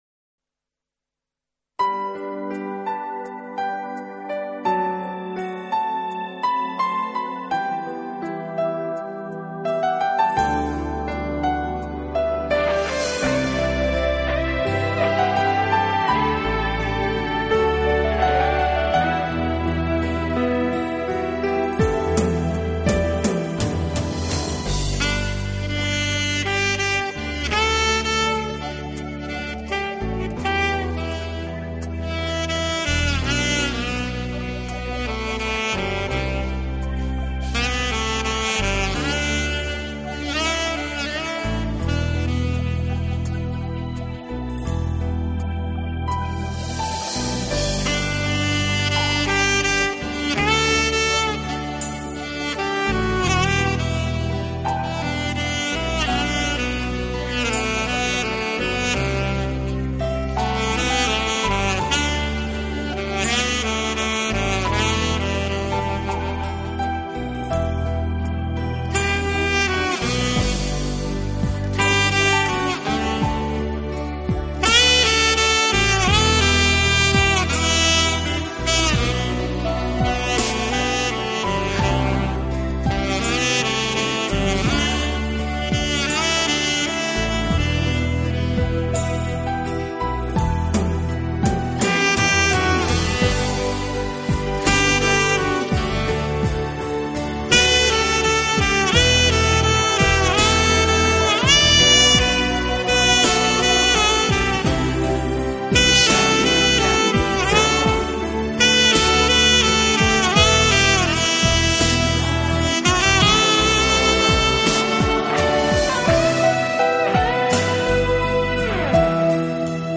【萨克斯纯音专辑】
专辑语种：萨克斯纯音专辑